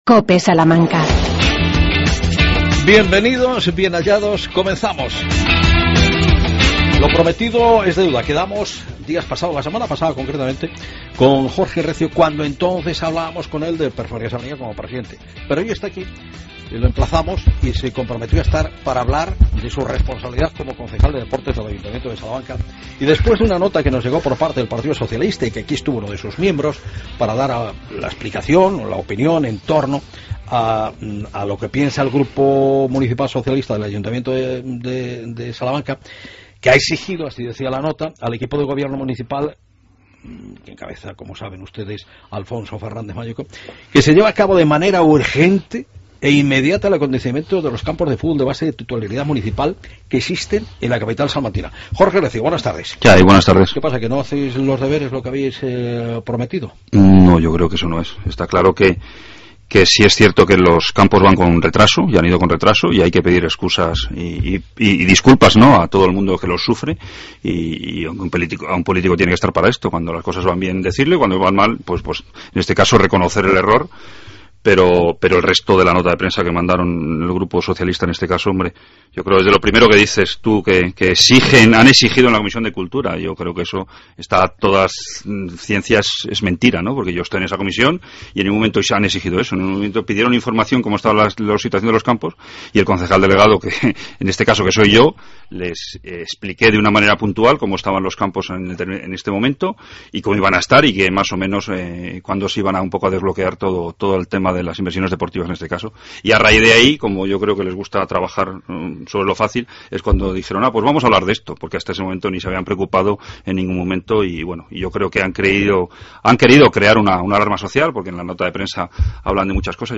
Redacción digital Madrid - Publicado el 24 sep 2014, 14:00 - Actualizado 14 mar 2023, 03:41 1 min lectura Descargar Facebook Twitter Whatsapp Telegram Enviar por email Copiar enlace Hablamos con el concejal de deportes del Ayuntamiento de Salamanca, Jorge Recio.